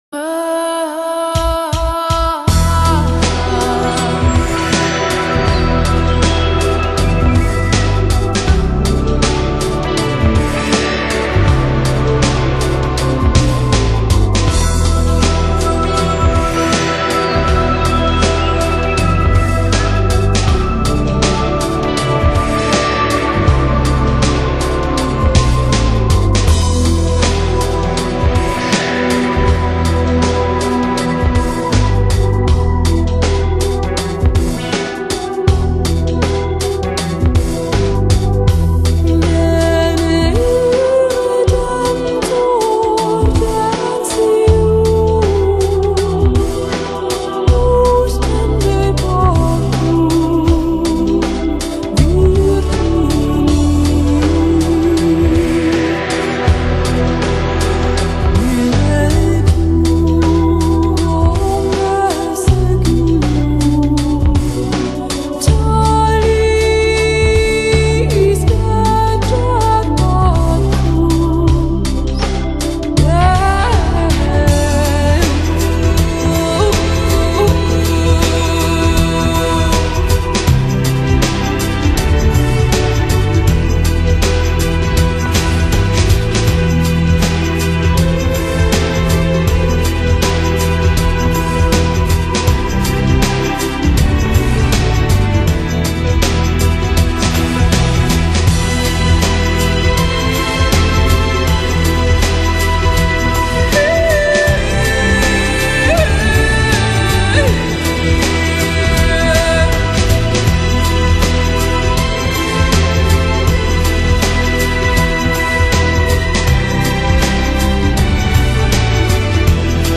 温软绵柔的女声